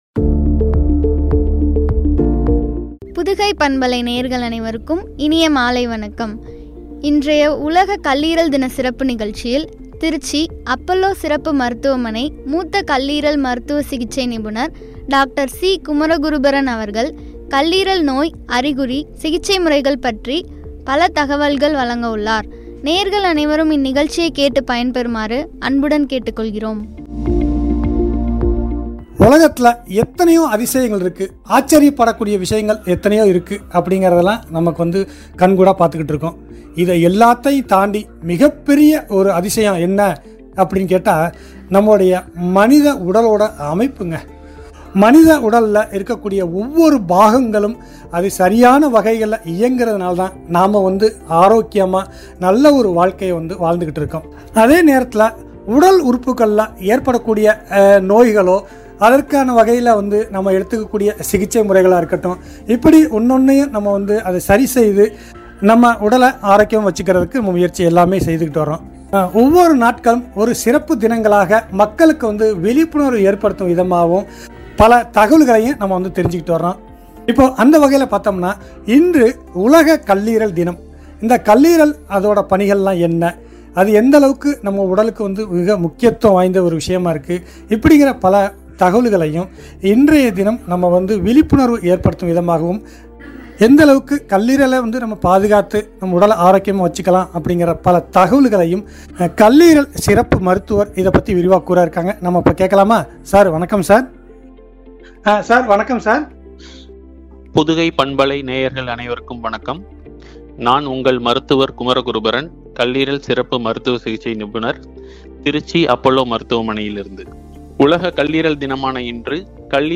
சிகிச்சை முறைகள்” குறித்து வழங்கிய உரையாடல்.